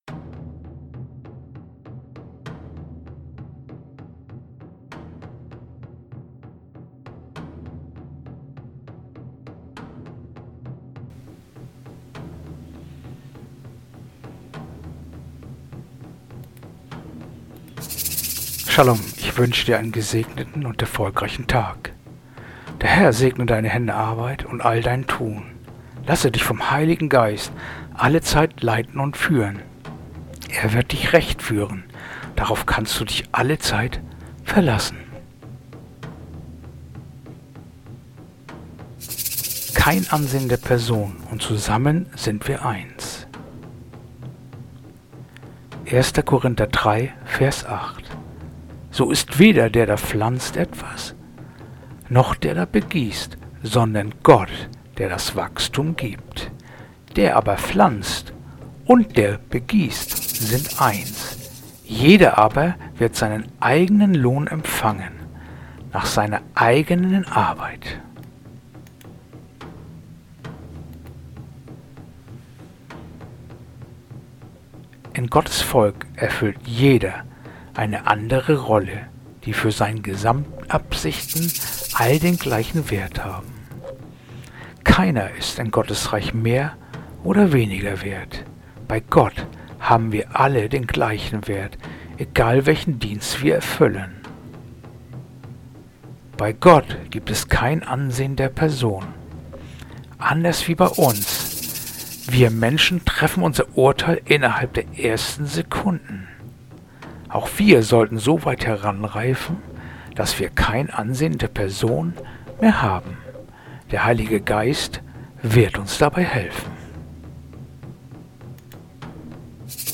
heutige akustische Andacht
Andacht-vom-03.-September-1-Korinther-3-8.mp3